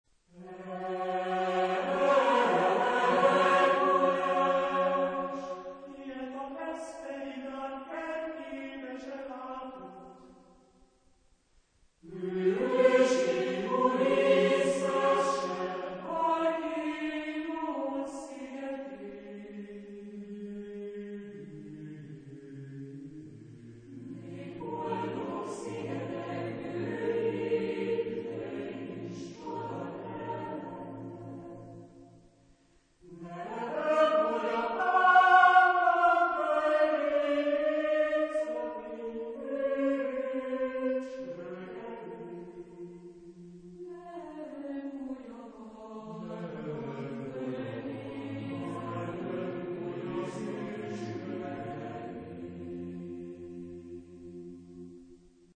Genre-Style-Forme : Chœur ; Profane
Type de choeur : SATB  (4 voix mixtes )